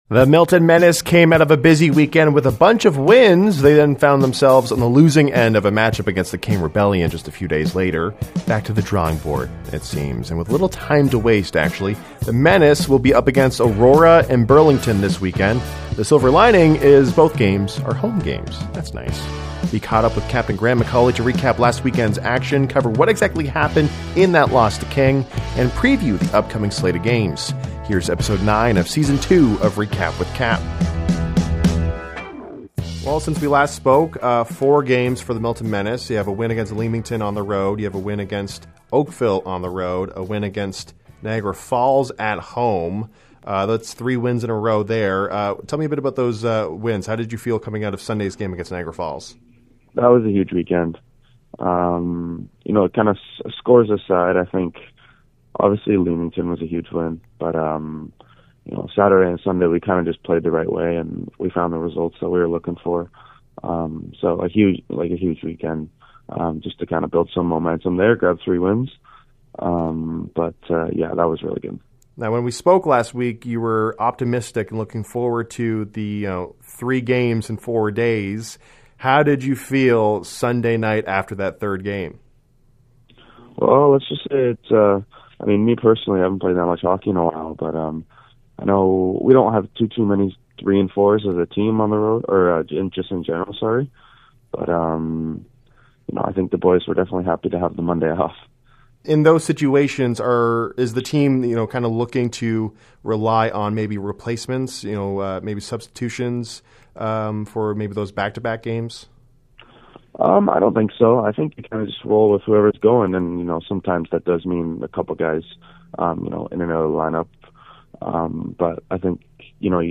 Weekly interview